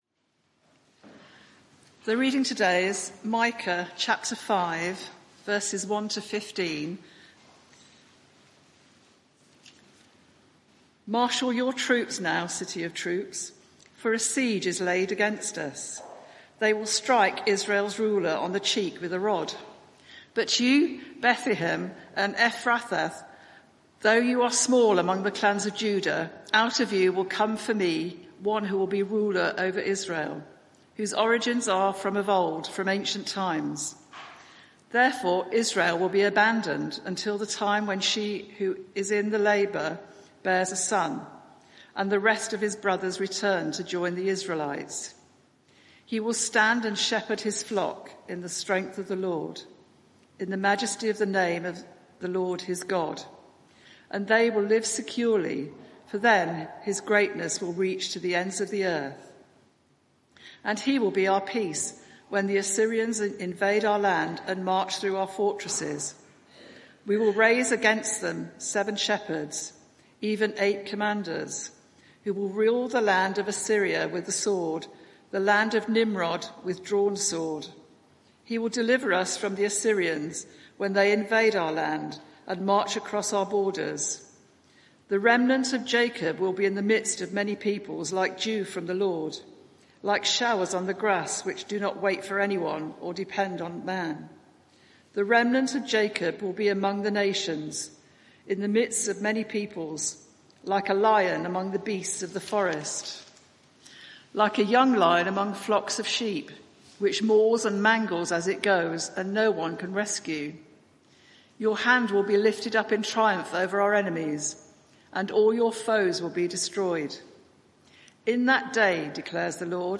Media for 11am Service on Sun 22nd Jun 2025 11:00 Speaker
Micah 5:1-15 Series: Who is like the Lord our God? Theme: Peace Promised There is private media available for this event, please log in. Sermon (audio) Search the media library There are recordings here going back several years.